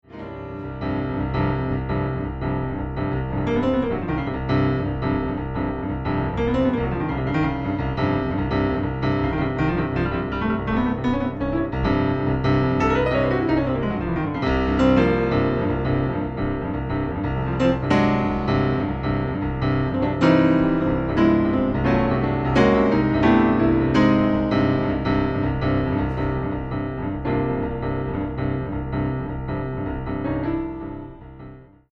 Solo Piano Concert
Recording: Ralston Hall, Santa Barbara, CA, January, 2008
Piano
Soundclip:  Fading in on the ostinato vamp section